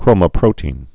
(krōmə-prōtēn, -tē-ĭn)